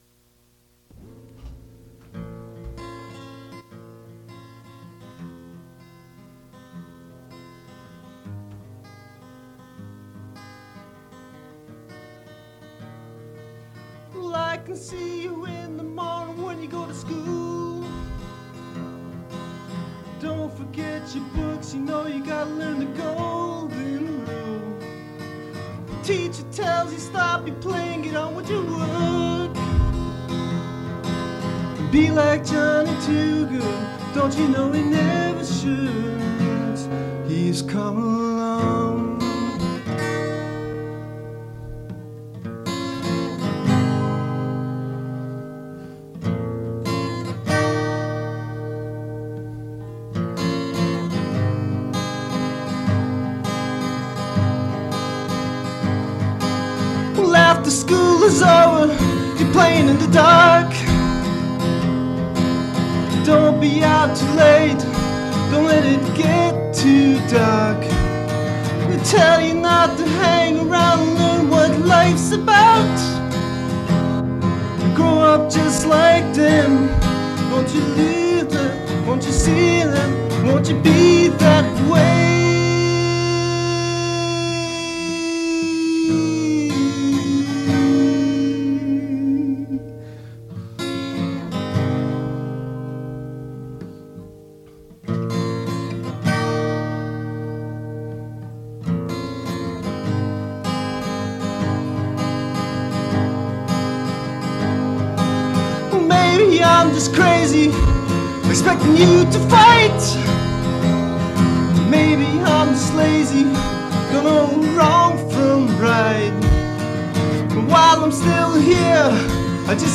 Fait partie de Acoustic reinterpretation of rock music